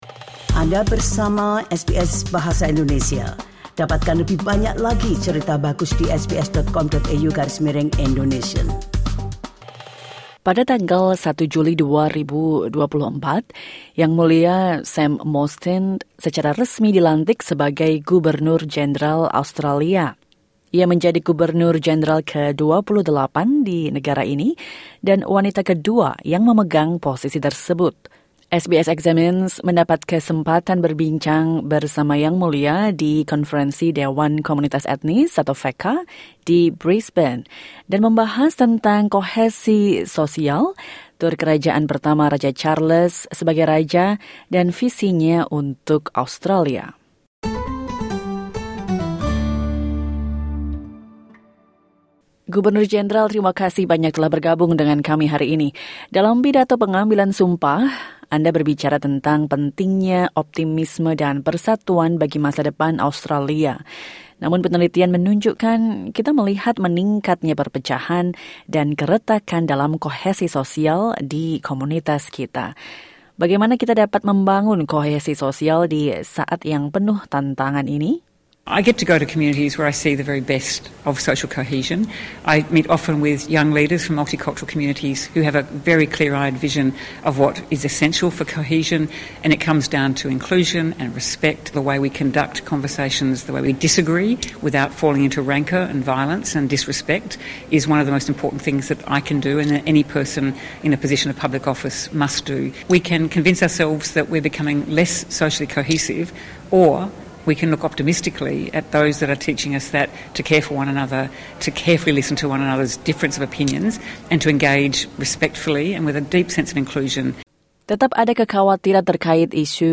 Dalam wawancara dengan SBS Examines, Yang Mulia Sam Mostyn berbagi pemikirannya tentang kohesi sosial, optimisme, dan peran monarki.